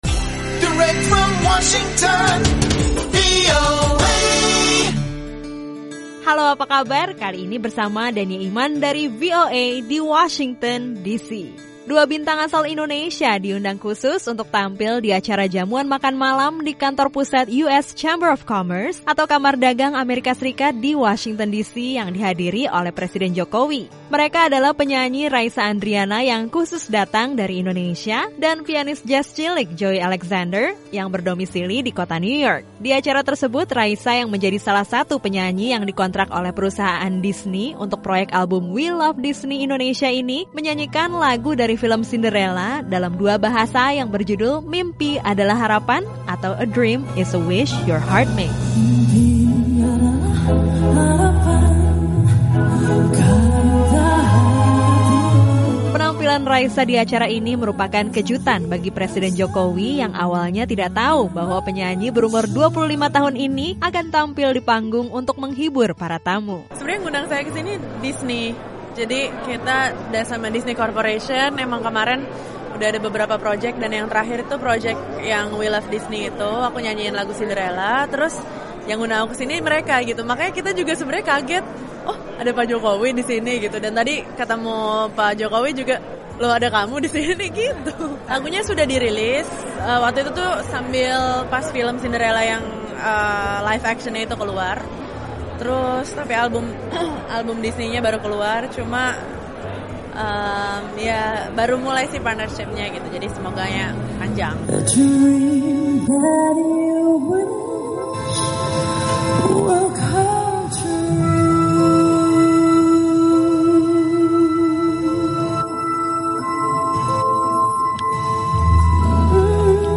Penyanyi Raisa dan pianis muda, Joey Alexander tampil di acara jamuan makan malam di kantor pusat US Chamber of Commerce atau kamar dagang Amerika Serikat di Washington, DC, yang dihadiri oleh presiden Jokowi.